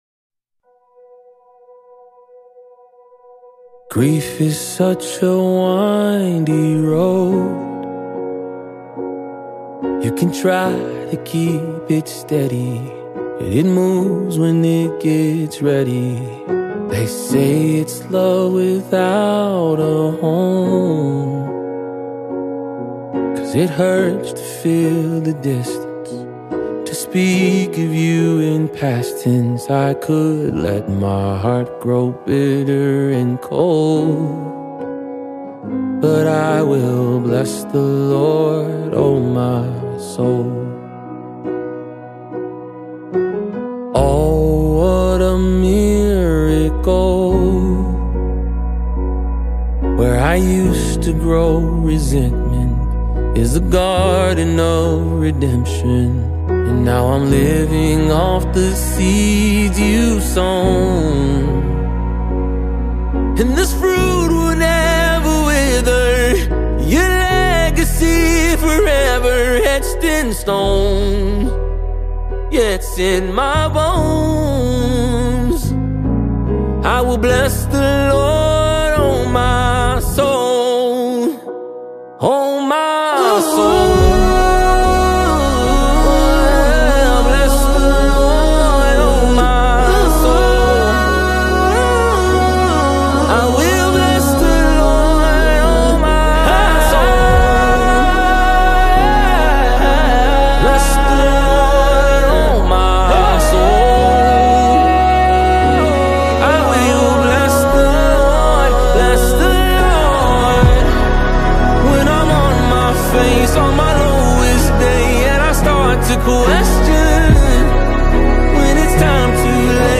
acclaimed worship leader and songwriter
With his trademark vulnerability and rich vocal expression